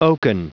Prononciation du mot oaken en anglais (fichier audio)
Prononciation du mot : oaken